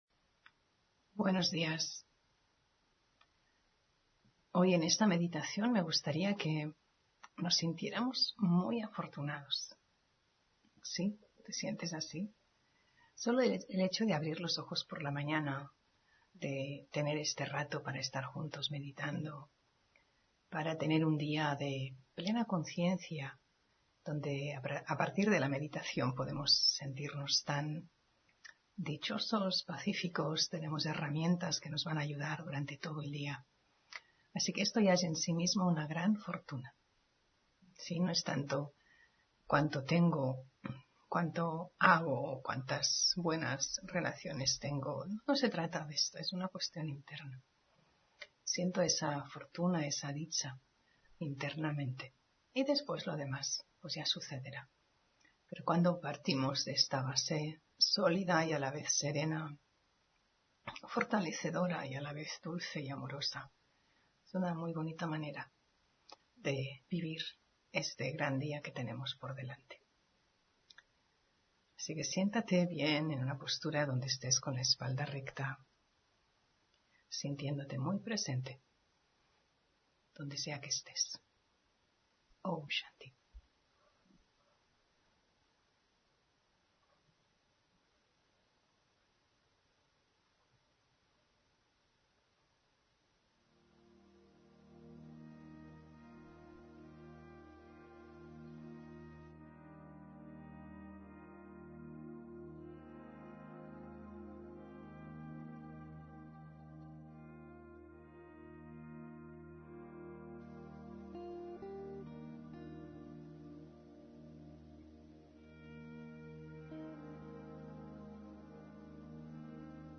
Meditación de la mañana